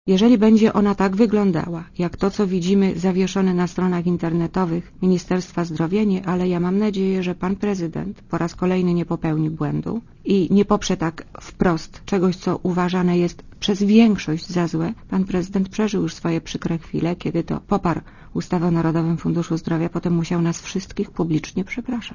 Zadeklarował to poniedziałkowy gość Radia ZET – posłanka Elżbieta Radziszewska.
Mówi Elżbieta Radziszewska z PO
radziszewska-zozy.mp3